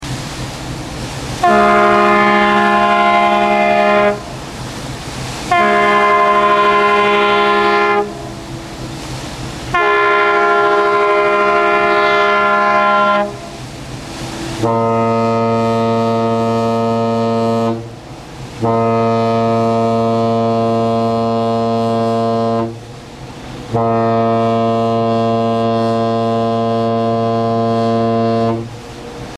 Begegnung MS Lofoten MS Vesteralen
Horn_Begegnung.mp3